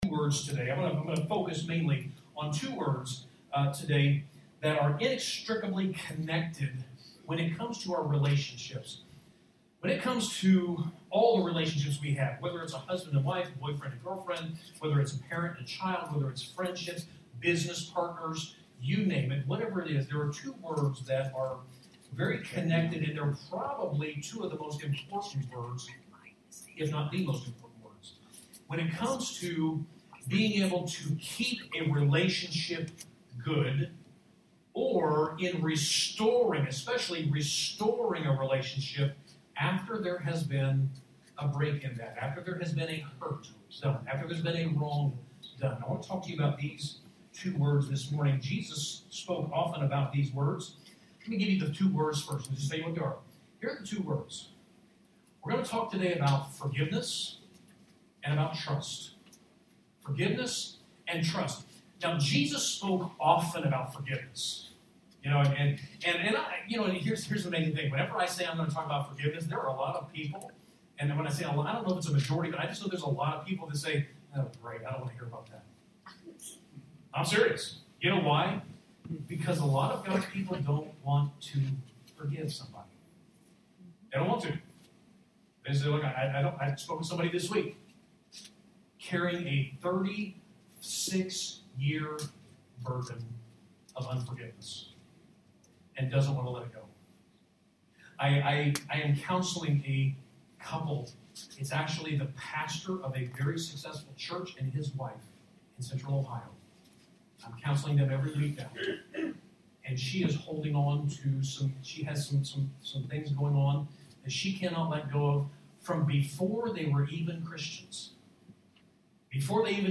3-21-21 Sunday Message: Forgiveness vs Trust